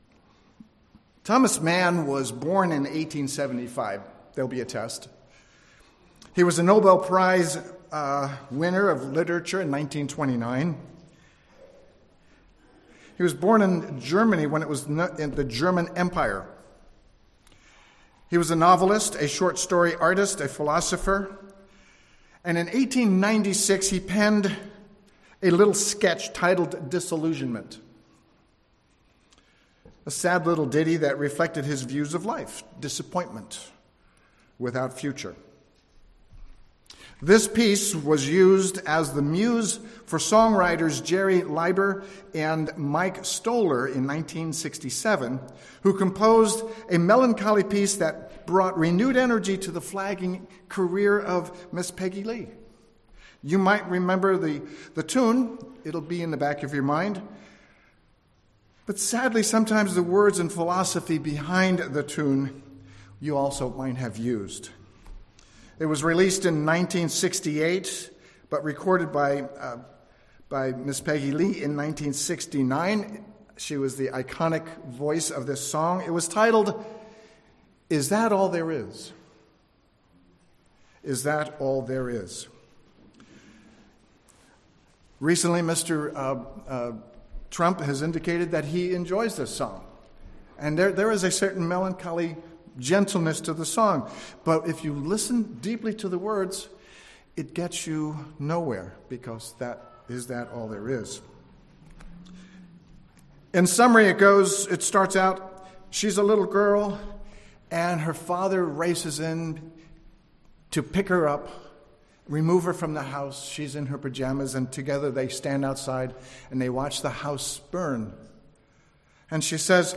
Listen to this sermon to learn about God's plan for mankind, and the mystery that He reveals to the saints. This life is not all there is!